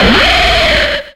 Cri d'Aéroptéryx dans Pokémon X et Y.